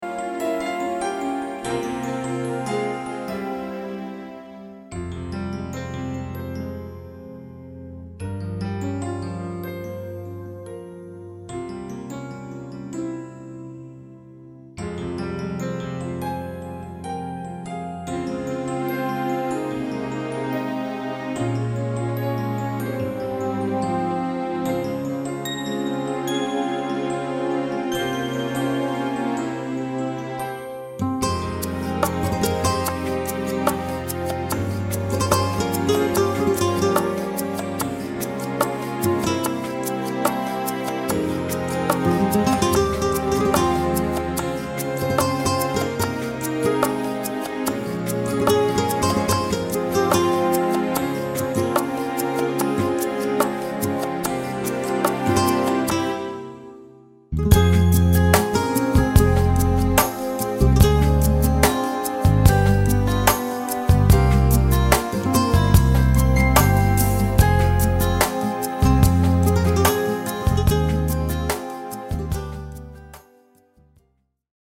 Genre: Pop-Ballade, Weihnachten
Hörprobe Karaoke (Radio Edit):